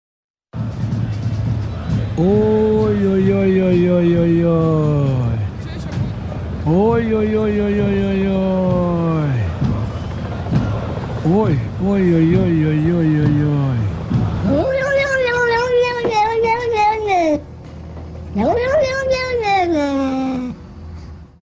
Звуки мужского голоса